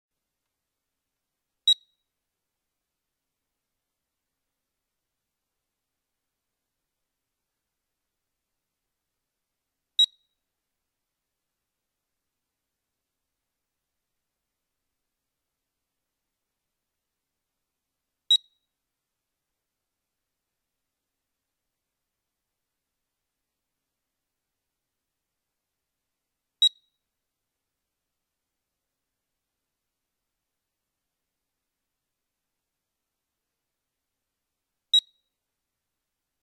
ご家庭で次の「ピッ！」という電子音を聞いたことがある……そんな人がいるかもしれない。
8秒ごとに「ピッ！」と鳴るこの電子音、いったい何の音だろうか？
冒頭で8秒ごとに「ピッ！」と鳴る電子音をご紹介したが、これは2007年以前の住宅用火災警報器が電池交換をうながす警告音だ。